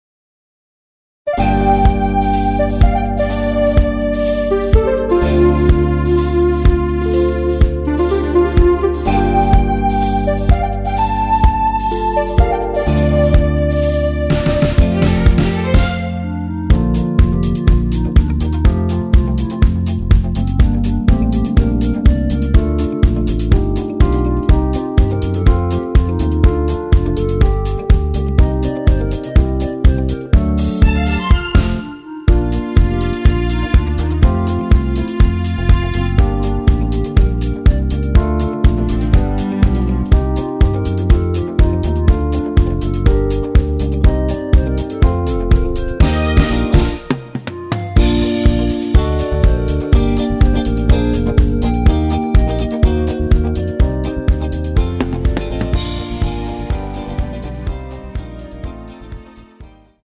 원키에서(-2)내린 멜로디 포함된 MR입니다.
Bb
앞부분30초, 뒷부분30초씩 편집해서 올려 드리고 있습니다.
중간에 음이 끈어지고 다시 나오는 이유는